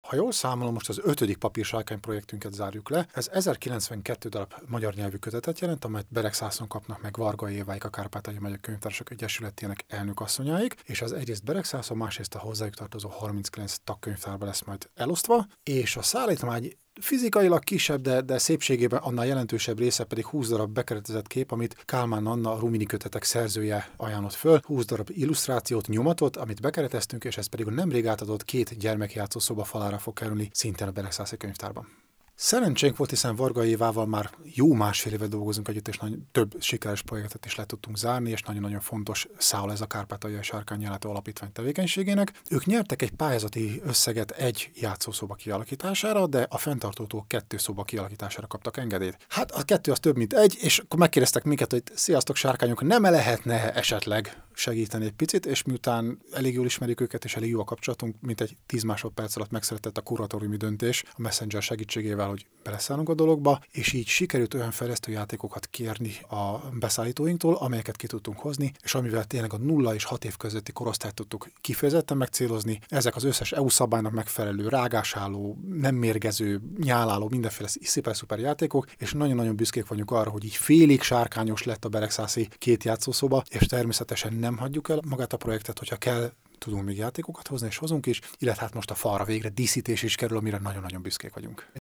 riport_sarrkany_0.mp3